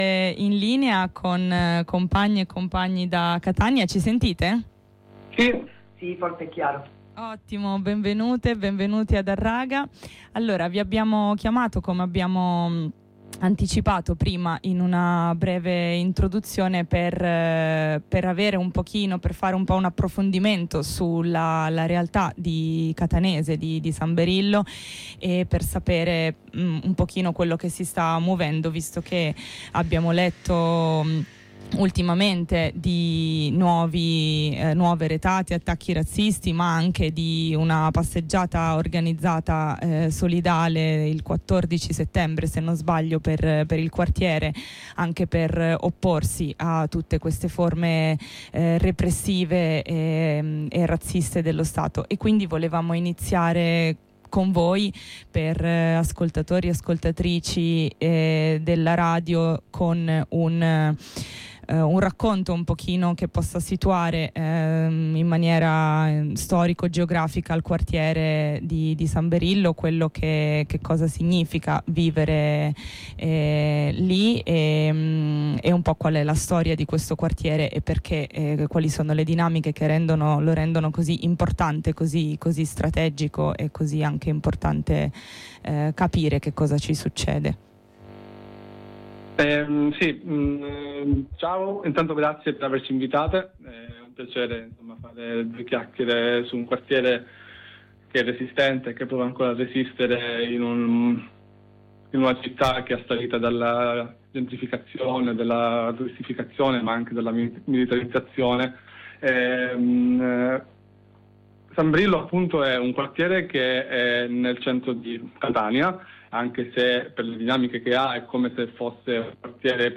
In questa puntata di Harraga, in onda su Radio Blackout ogni venerdì alle 15, insieme ad alcunx compagnx di Catania ripercorriamo la storia di San Berillo per arrivare all’attualità; con un’analisi delle responsabilità e delle dinamiche di questo processo di saccheggio e distruzione del quartiere, dai grandi investitori alle cooperative sociali, dai locali e i progetti artistici al comitato razzista di quartiere. Per poi ribadire la necessità di stare al fianco della resistenza autorganizzata dellx abitanti del quartiere creando reti di solidarietà e connessioni tra le lotte.